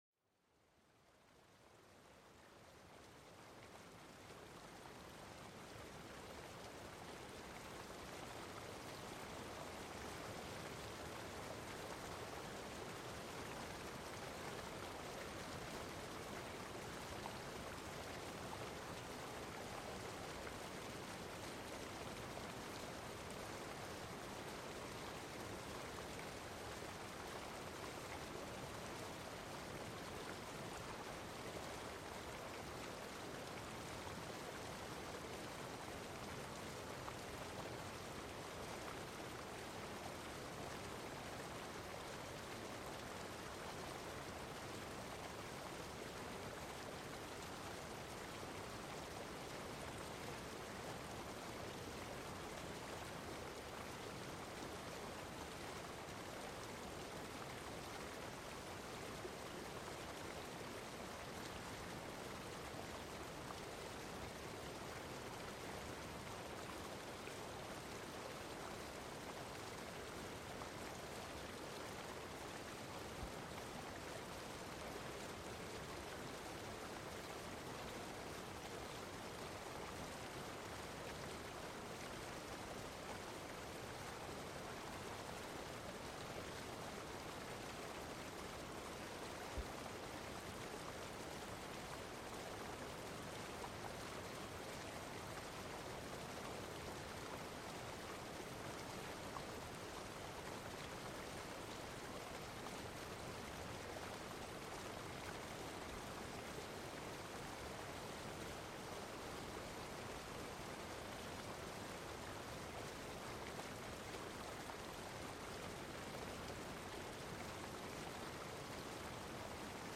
SONIDOS DE LA NATURALEZA PARA LA RELAJACIÓN